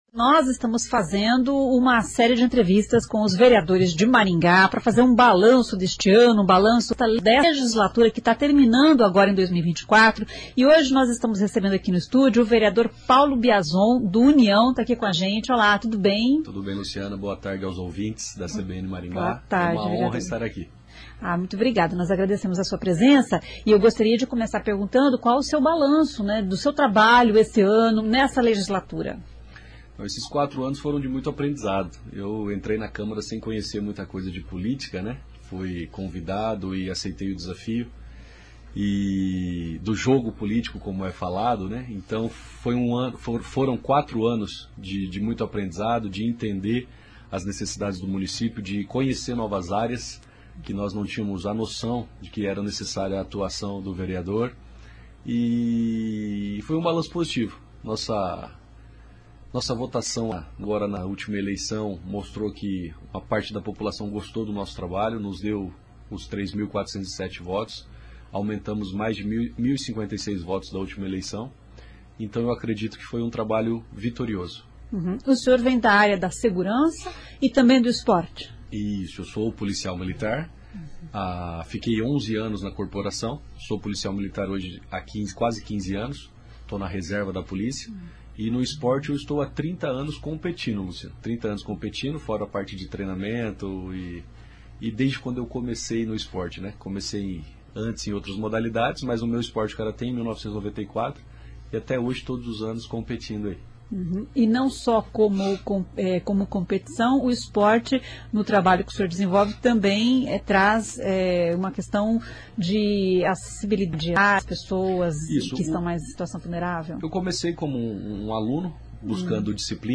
O vereador Paulo Biazon (União) faz um balanço do primeiro mandato na Câmara Municipal de Maringá.